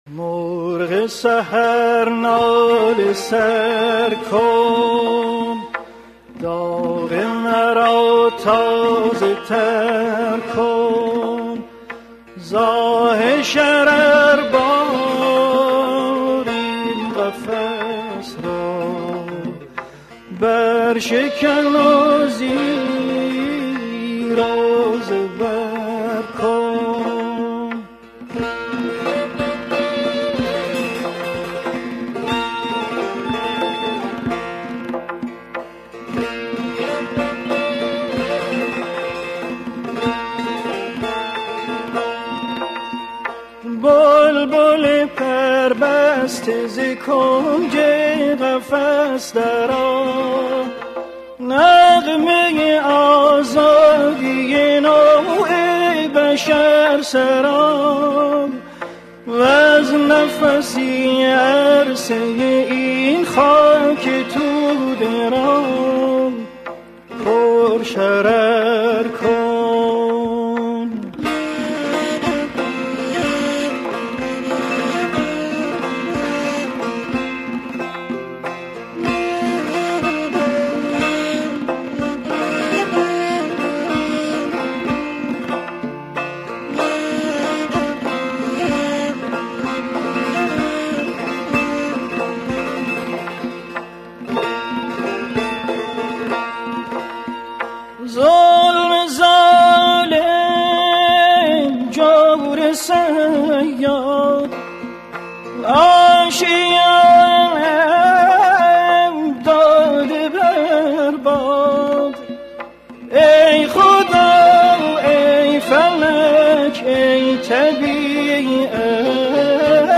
موسیقی سنتی